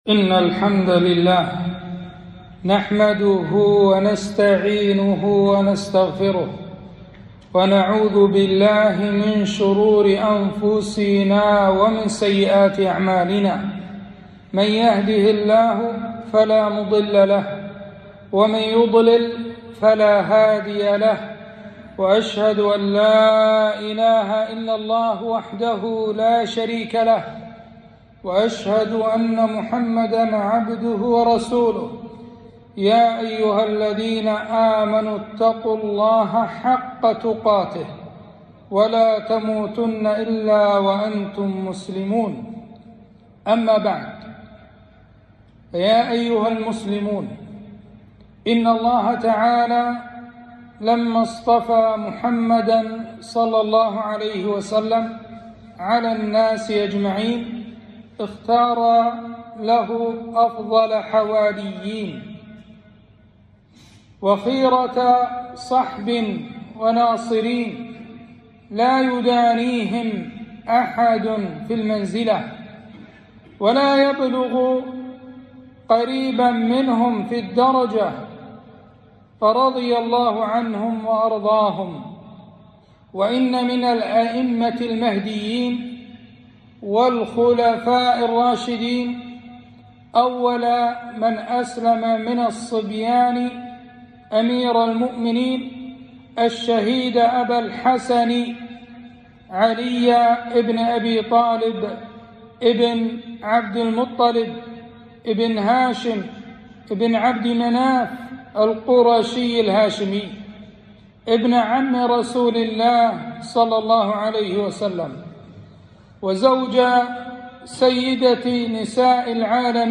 خطبة - مناقب أمير المؤمنين علي بن أبي طالب رضي الله عنه